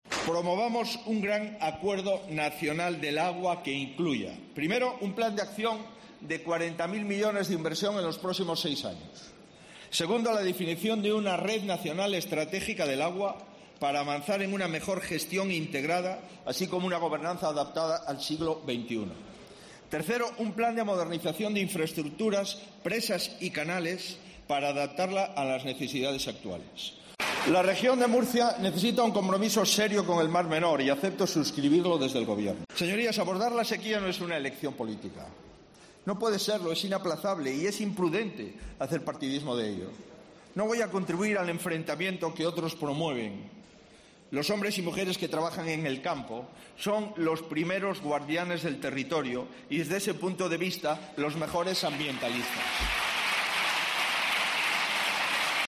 DEBATE INVESTIDURA
Así lo ha avanzado el candidato a la Presidencia este martes en su discurso de investidura que se celebra en el Congreso de los Diputados, donde ha ofrecido a la Cámara que si le da su respaldo pondrá en marcha seis pactos de Estado, entre los que destaca un Pacto Nacional por el Agua que de solución a la sequía y evite el enfrentamiento entre los territorios.